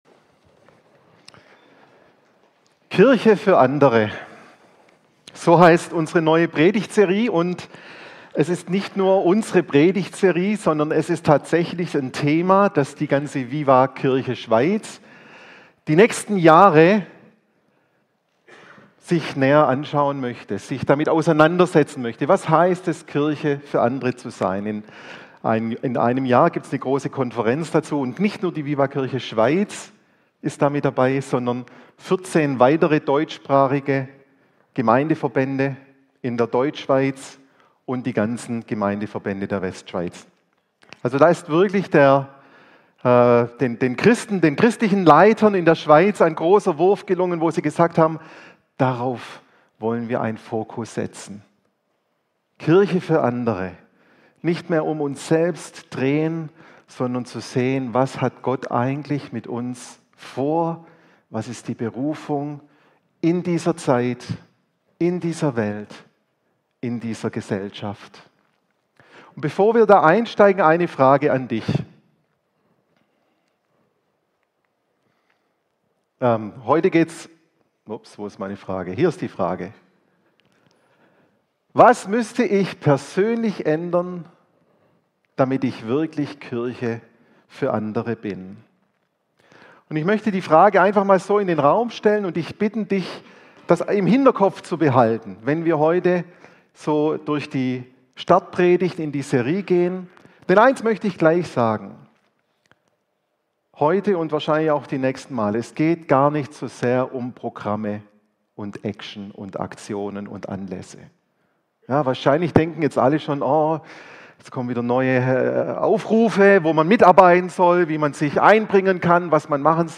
Die neueste Predigt